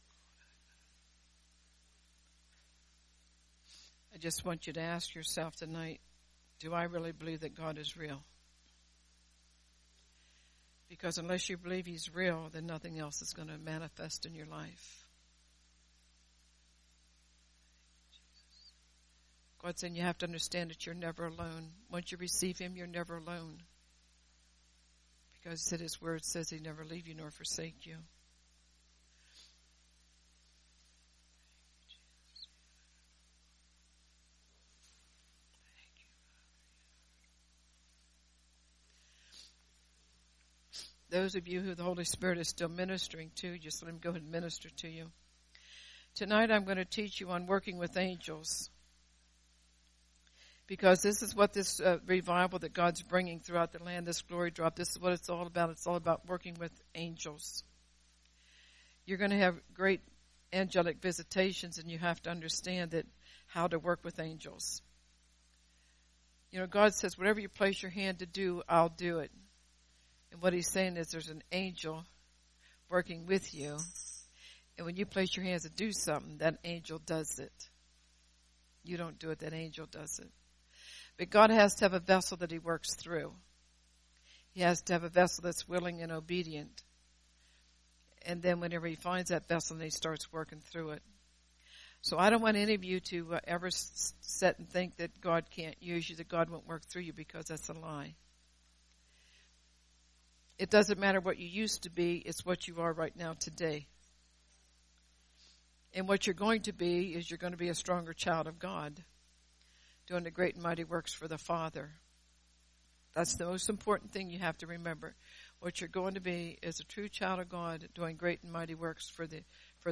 From the “Great Miracle Service.”